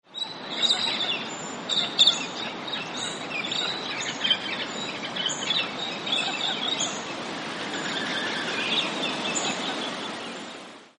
Galah - Cacatua roseicapilla
Voice: high pitched 'chill, chill', harsh screeching.
Call 1: flock calling while flying; Adelaide Rosellas call about halfway through the recording.
Galah_flock.mp3